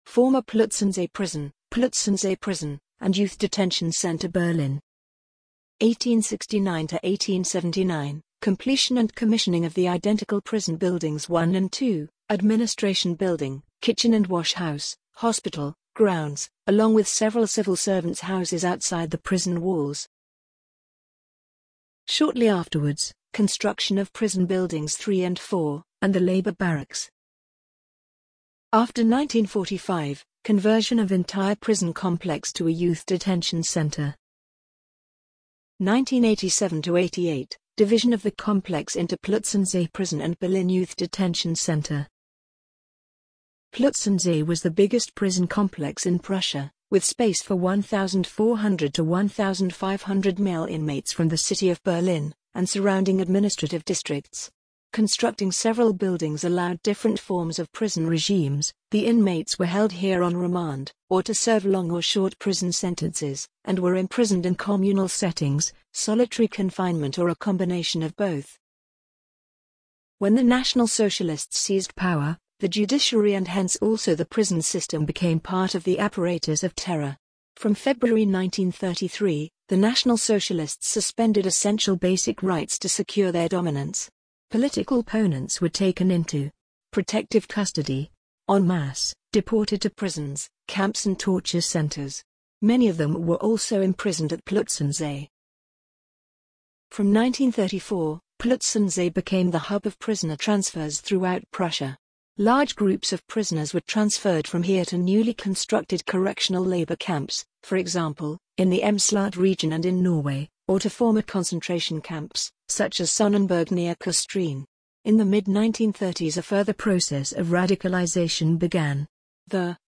Audio-Version des Artikels